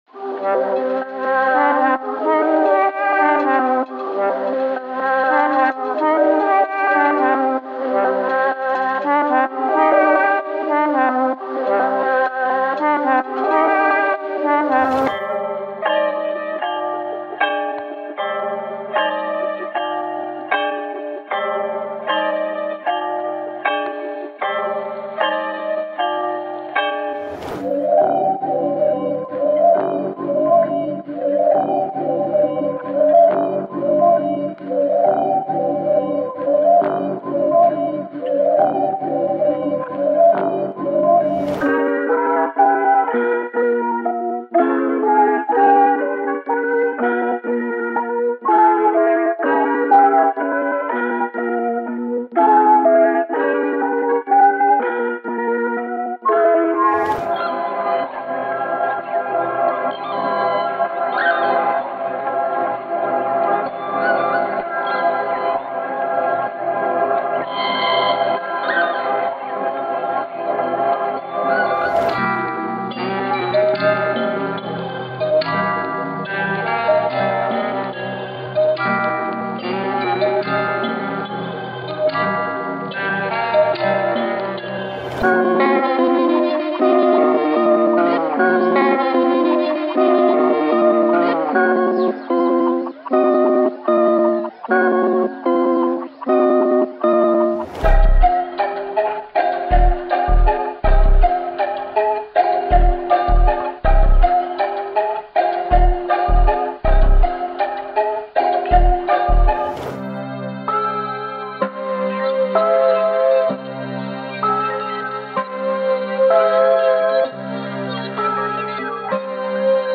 完美模拟复古/模拟音色。